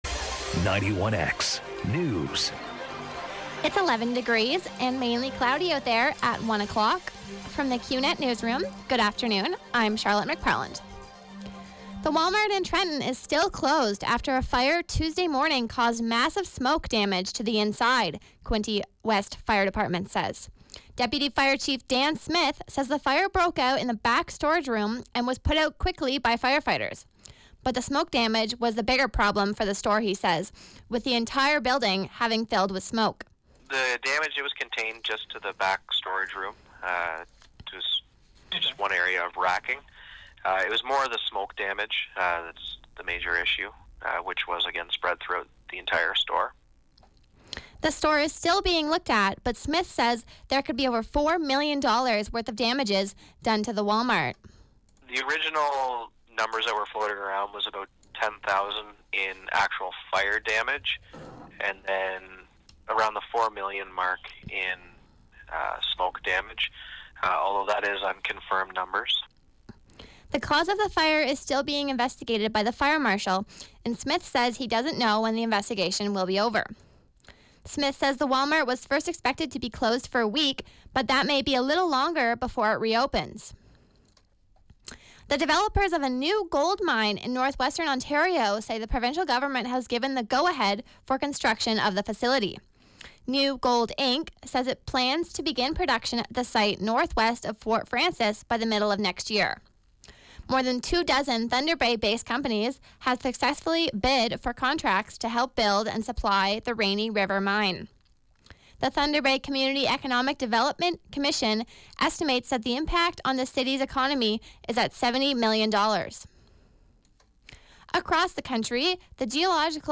91X Newscast- Wednesday, Nov. 16, 2016, 1 p.m.